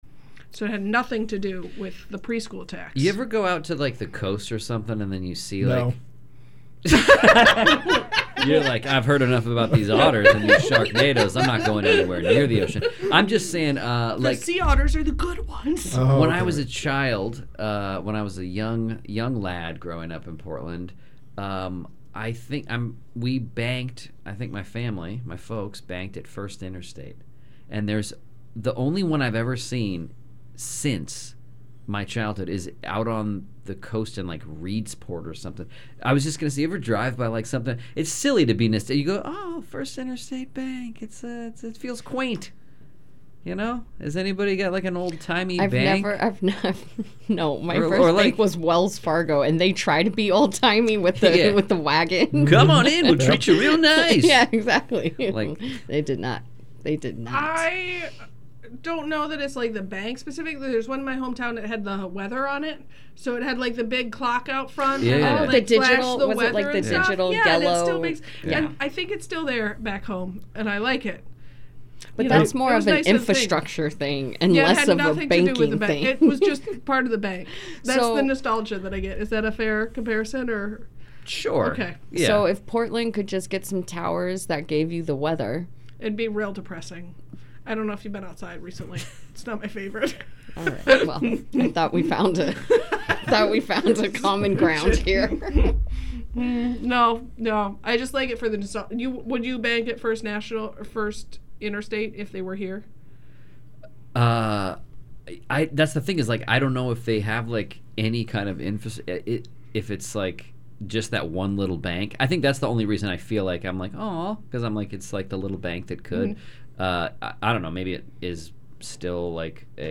Comics Take is will be joined by three PNW comedians to breakdown the local news and other things on their mind and in their trauma.
Satire News Panel Show